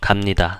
-(su)pnita
kapnita deferential